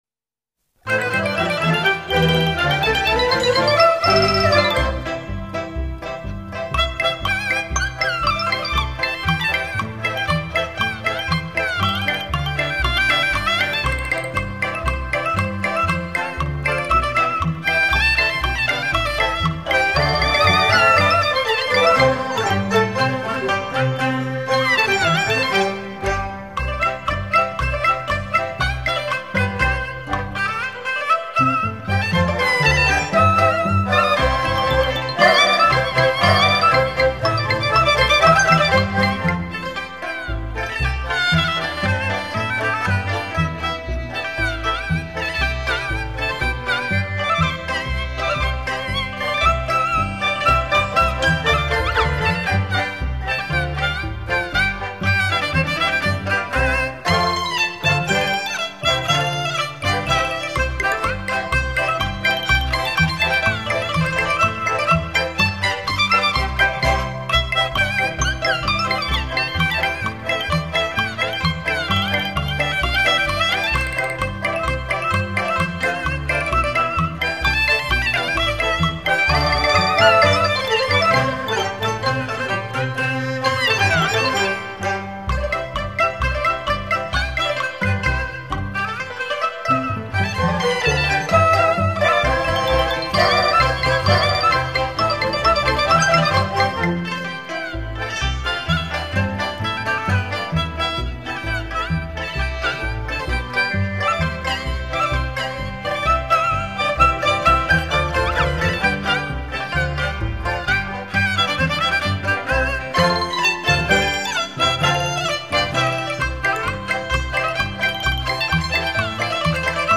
附上我比较喜欢的曲子，是不是很有过年气氛：）广东朋友不可能没听过的哦。